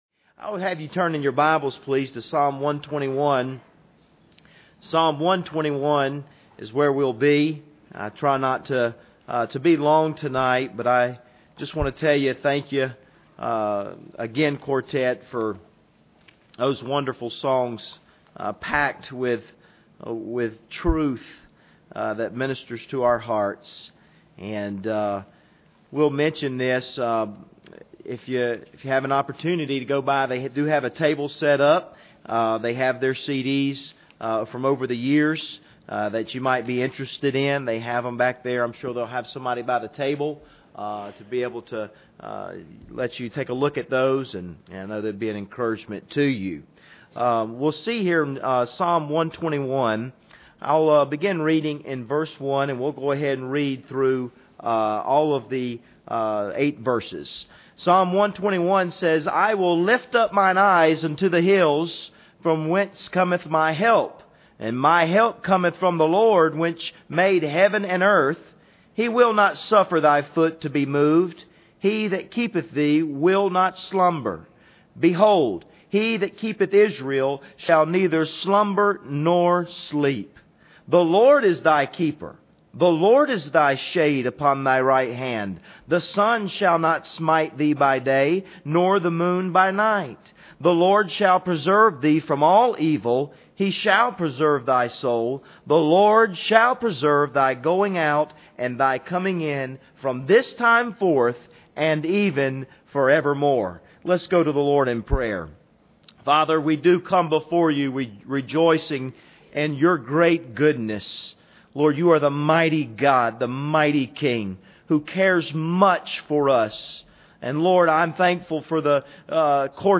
Passage: Psalm 121:1-8 Service Type: Sunday Evening